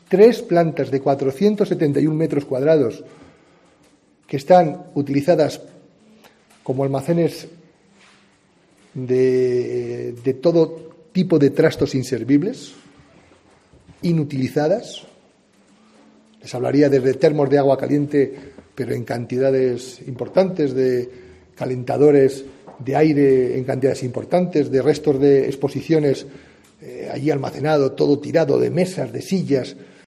José Mazarías, alcalde de Segovia, sobre el espacio bajo la escuela infantil La Senda